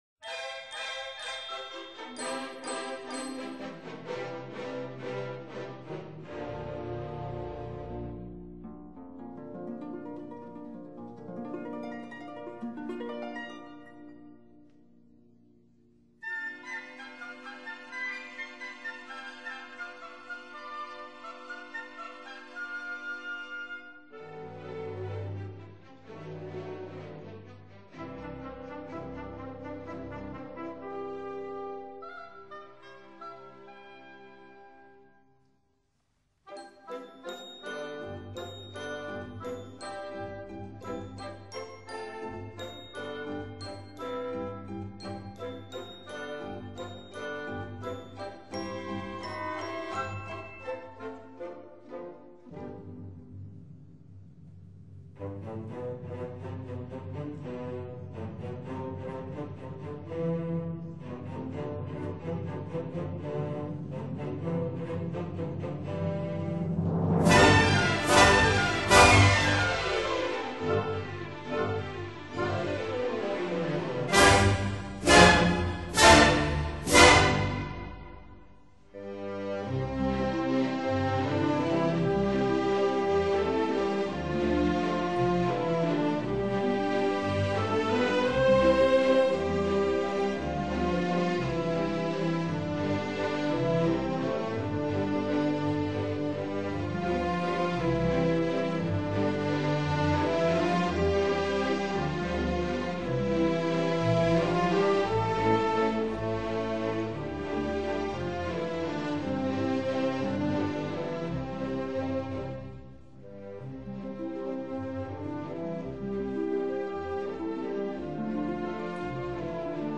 Walz